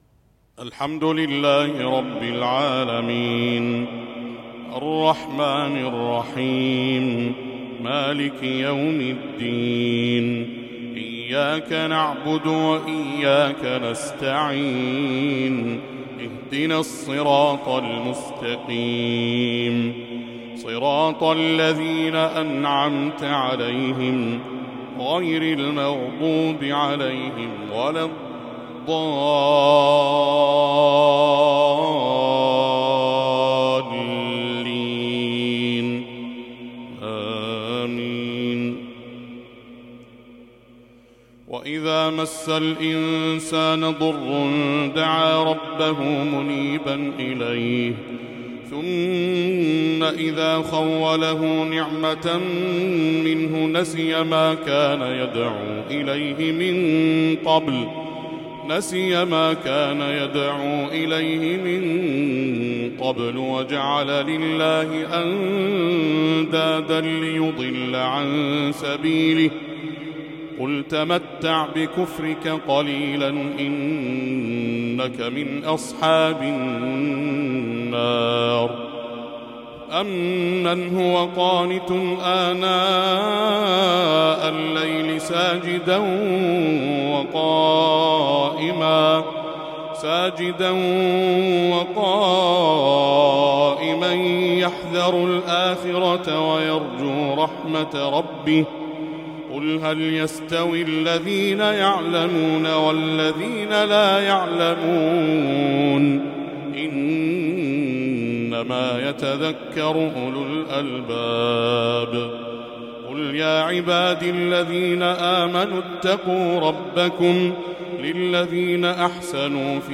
تلاوة جميلة من سورة الزمر